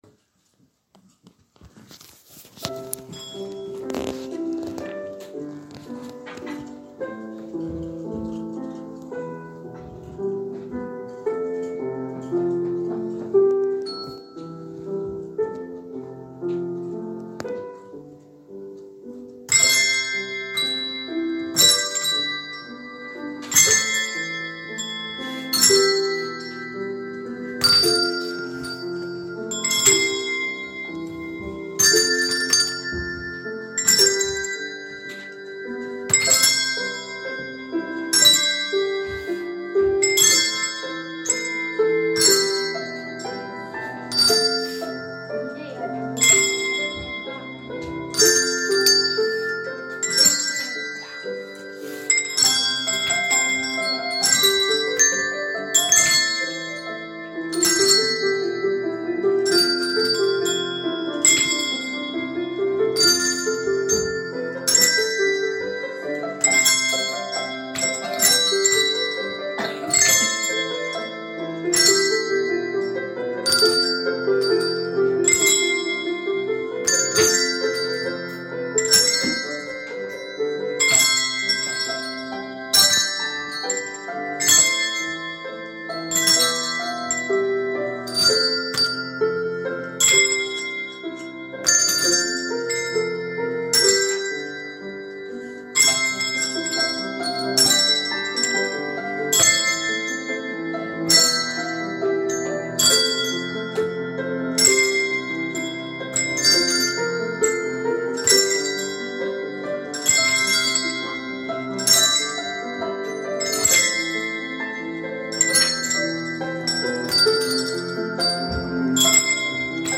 And also, a couple of pieces of music performed by Year 4 on bells.